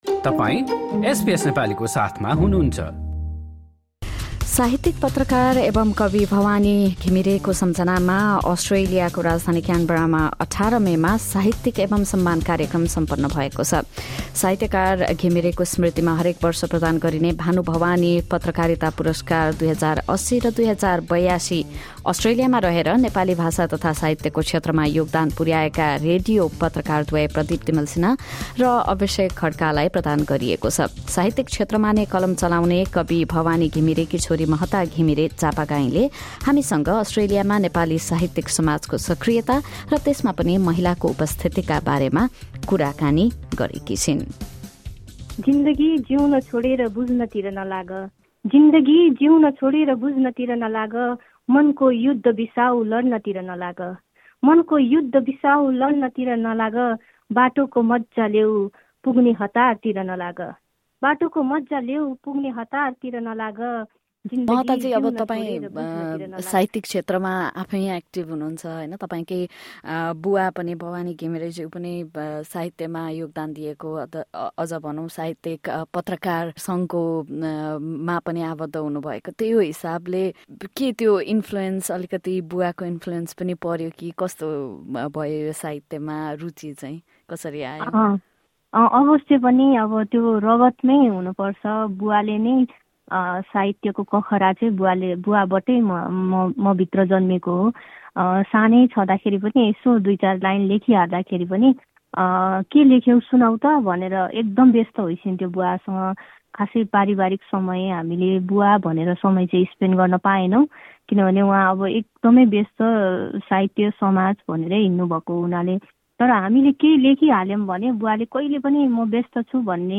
एसबीएस नेपालीसँग गरेको कुराकानी